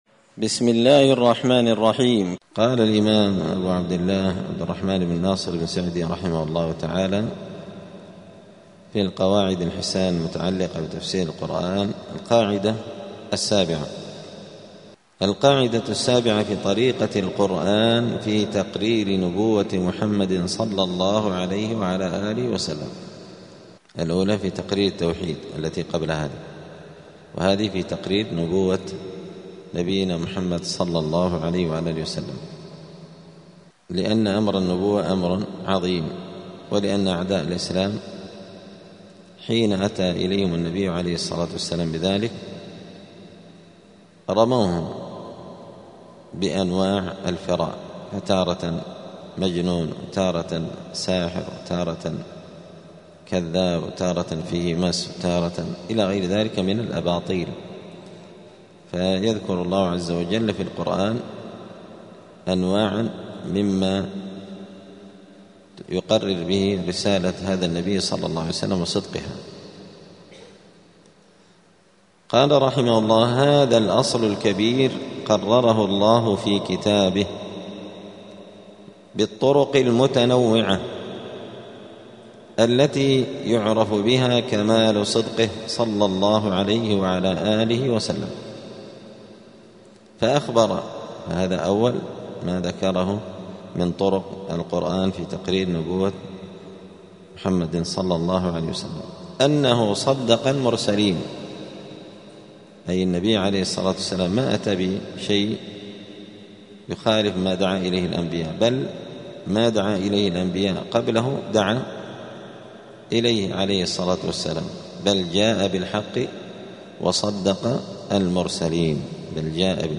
دار الحديث السلفية بمسجد الفرقان قشن المهرة اليمن
الثلاثاء 11 رمضان 1446 هــــ | التعليق لابن عثيمين على القواعد الحسان المتعلقة بتفسير القرآن للإمام السعدي رحمه الله، الدروس، دروس القران وعلومة | شارك بتعليقك | 19 المشاهدات